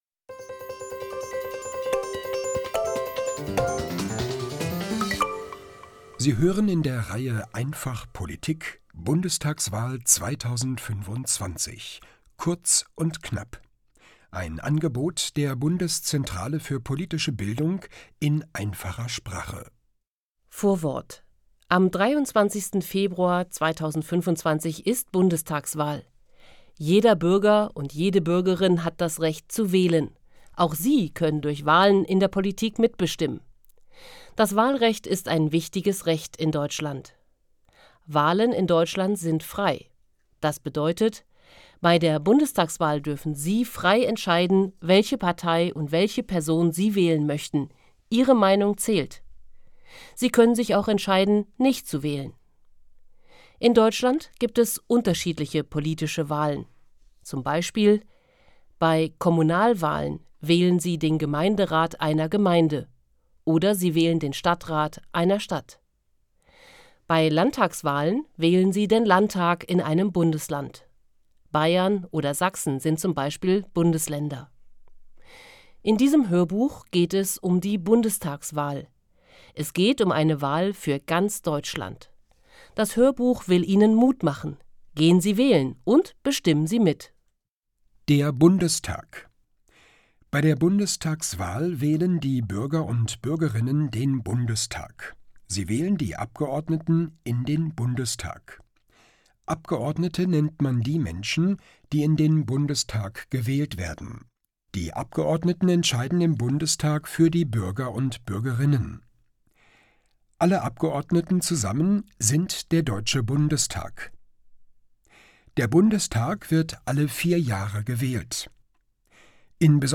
Bundestagswahl 2025. Kurz und knapp Hörbuch in einfacher Sprache